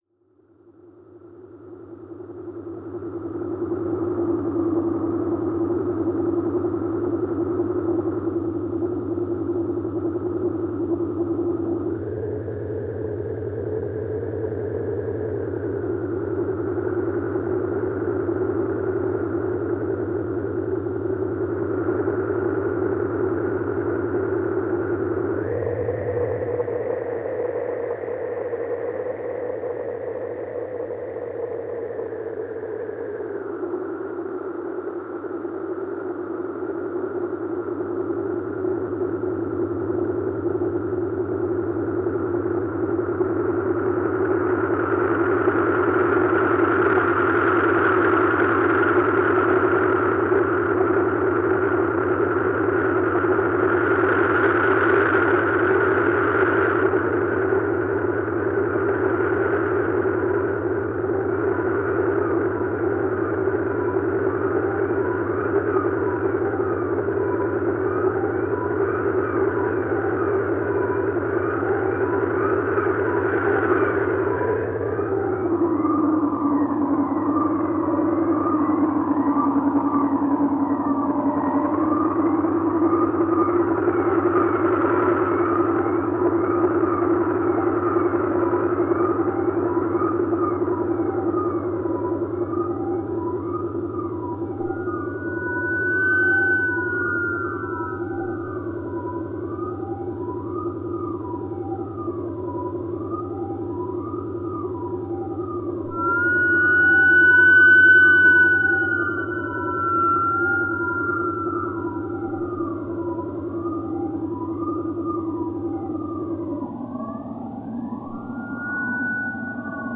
Ambiance sonore avec impro mélodique sur Model-D au ruban. Séquence en boucle déterminant un rythme et une tonalité.